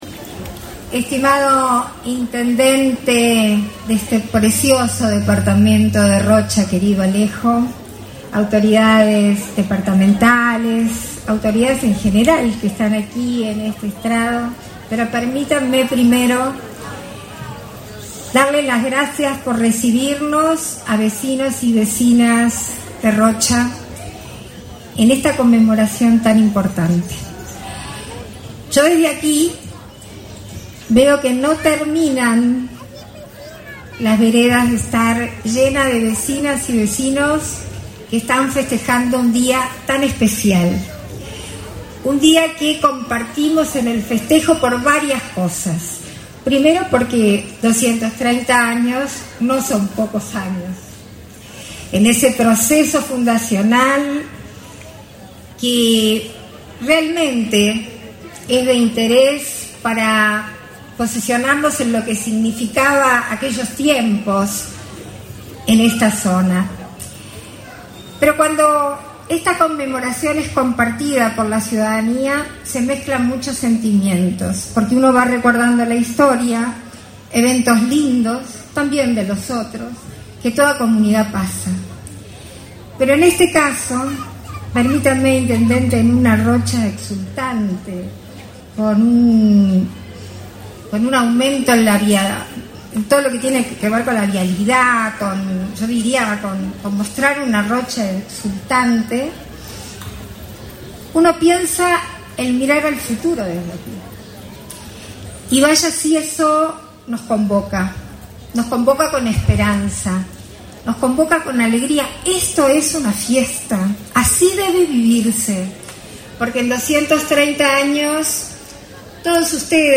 Palabras de la presidenta de la República en ejercicio, Beatriz Argimón, en aniversario de Rocha
Con la presencia de la presidenta de la República en ejercicio, Beatriz Argimón, se realizó, este 23 de noviembre, el acto oficial por el 230.°